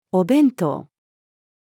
お弁当-female.mp3